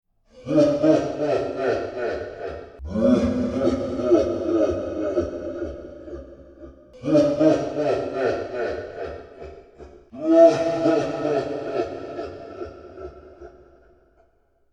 Download Ghost Laugh sound effect for free.
Ghost Laugh